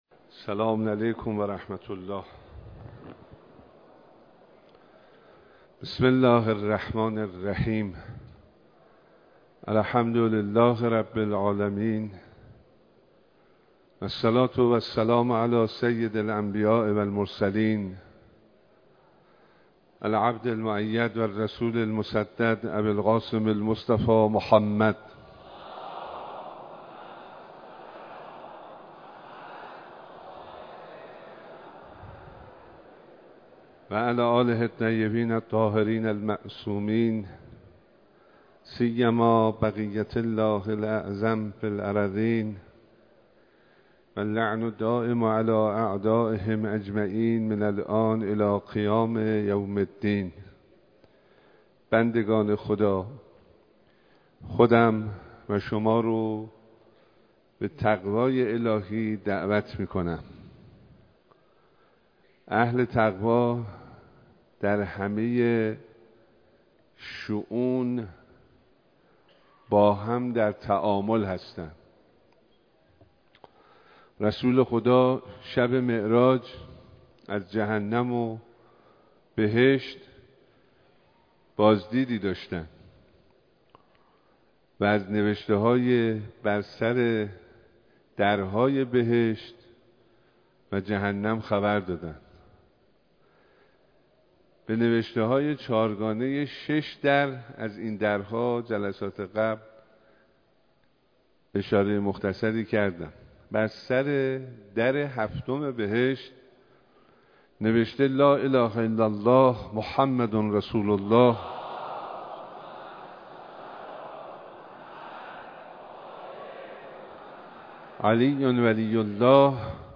ایراد خطبه‌های نماز جمعه شهرستان کرج به امامت آیت‌الله حسینی همدانی نماینده ولی‌فقیه در استان البرز و امام‌جمعه کرج
صوت خطبه‌های نماز جمعه بیست و یکم اردیبهشت‌ماه شهرستان کرج
به گزارش روابط عمومی دفتر نماینده ولی‌فقیه در استان البرز و امام‌جمعه کرج، نماز جمعه بیست و یکم اردیبهشت‌ماه هزار و چهارصد و سه شهرستان کرج به امامت آیت‌الله حسینی همدانی در مصلای بزرگ امام خمینی (ره) برگزار شد.